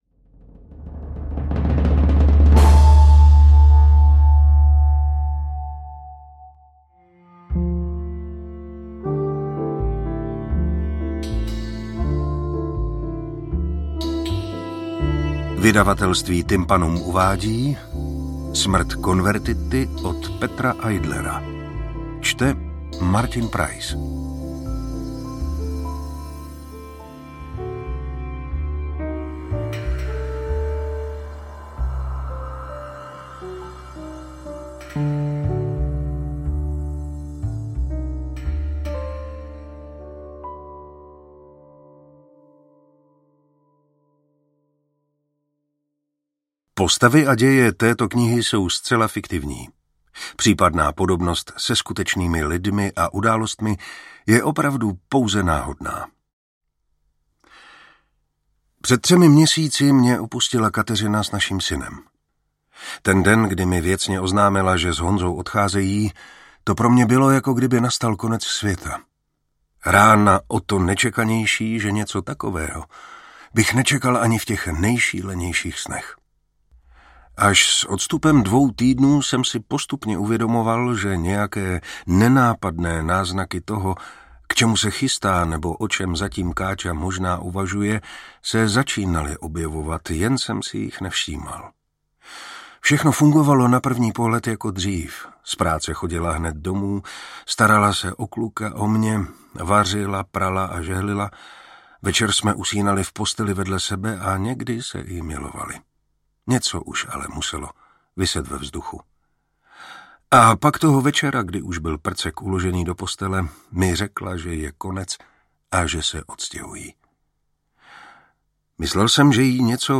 Interpret:  Martin Preiss
AudioKniha ke stažení, 40 x mp3, délka 8 hod. 4 min., velikost 445,5 MB, česky